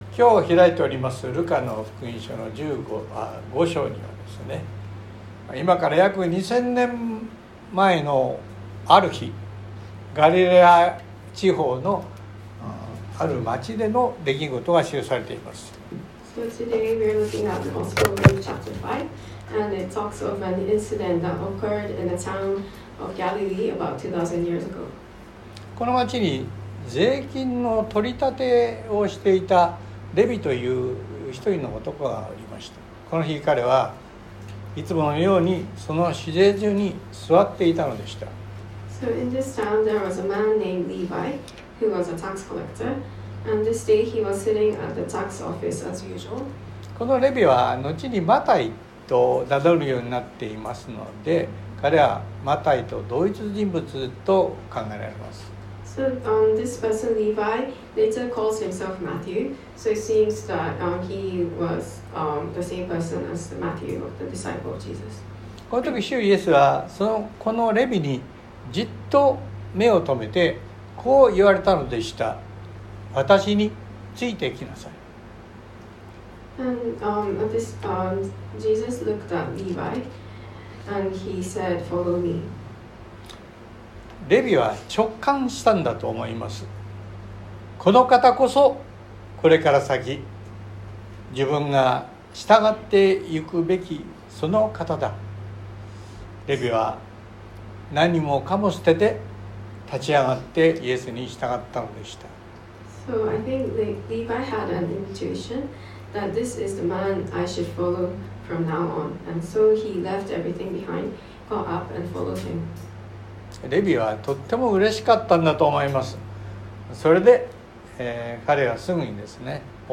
↓Audio link to the sermon:(Sunday worship recording) (If you can’t listen on your iPhone, please update your iOS) 27 After this, Jesus went out and saw a tax collector by the name of Levi sitting at his tax booth.